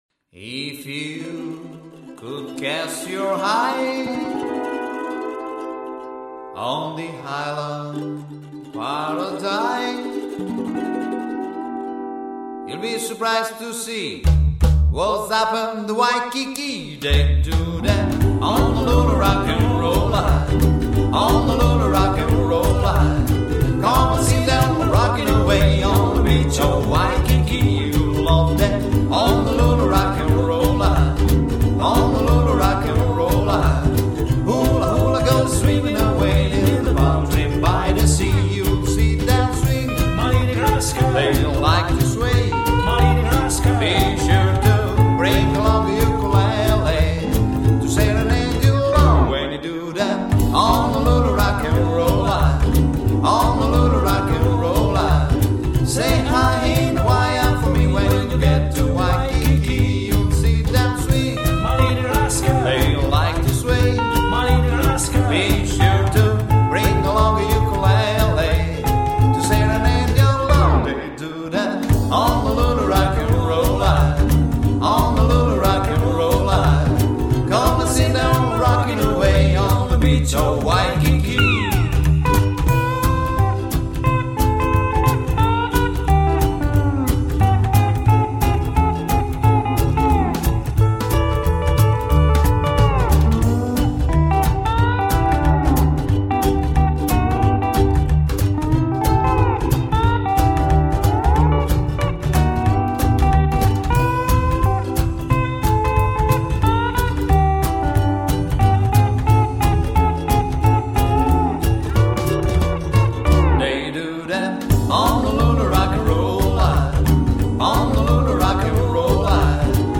Gesang und Gitarre
Kontrabass
Percussion
Gitarre und Ukulele